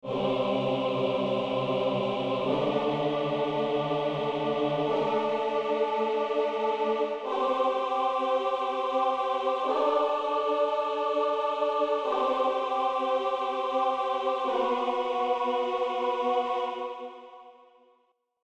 Sin-tritono.mp3